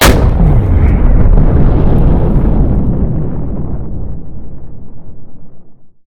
rocket.ogg